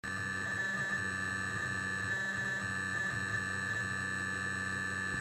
TMS320F28069M: summing noise lab11e
If the motor isn't spinning, there is a “summing noise”.